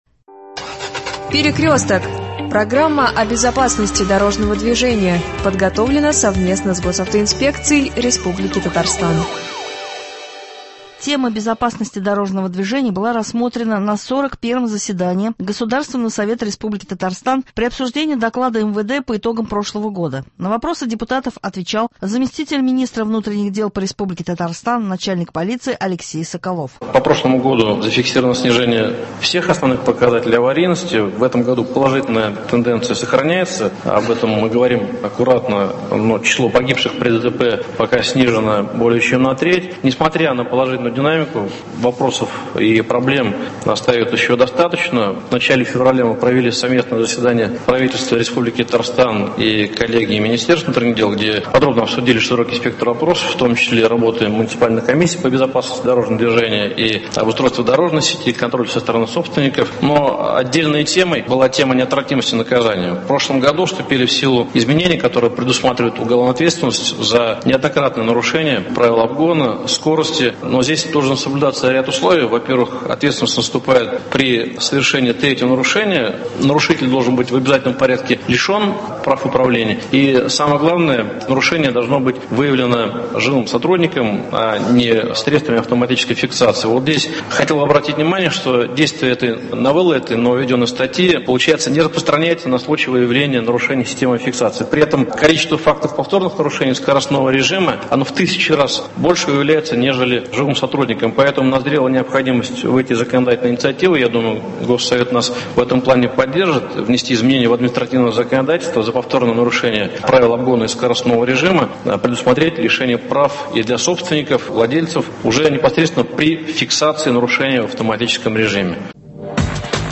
Тема БДД была рассмотрена на 41 заседании ГС РТ при обсуждении доклада МВД по итогам прошлого года. На вопросы депутатов ответил Заместитель министра внутренних дел по РТ — начальник полиции Алексей Соколов.